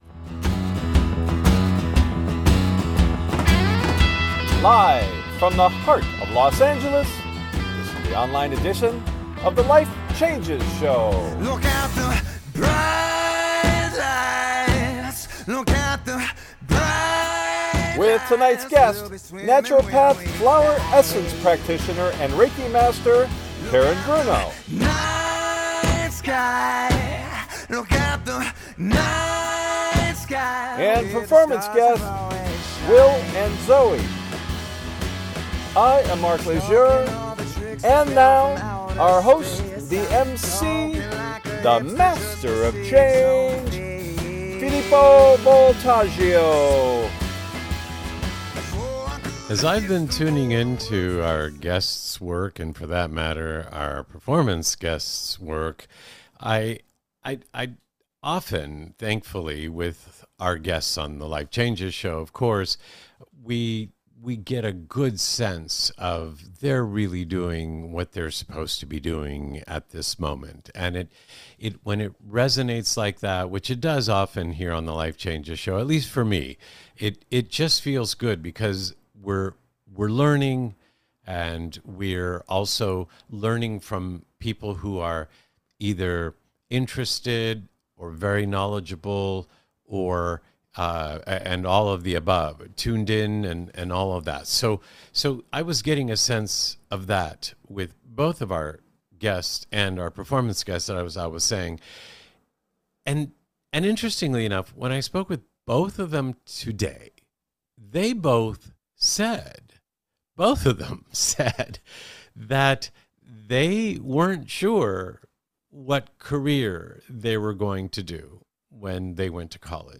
Talk Show Episode
American Country-Folk Music Duo